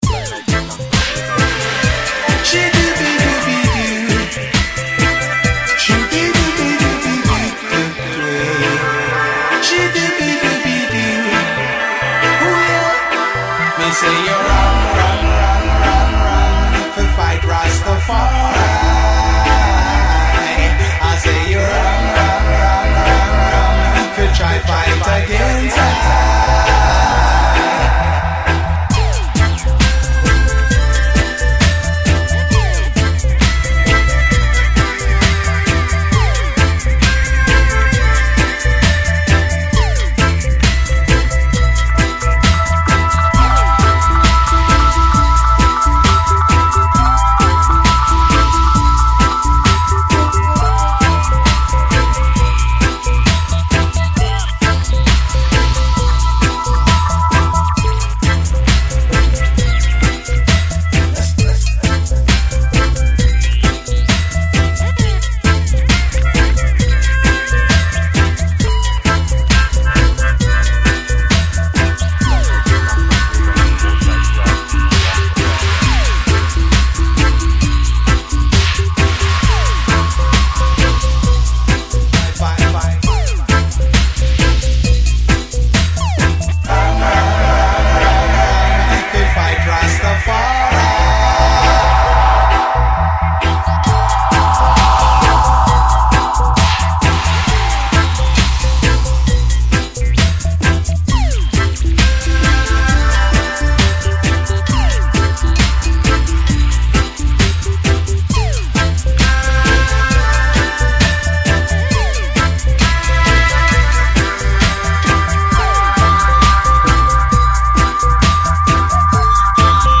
dub version